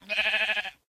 sheep_say2.ogg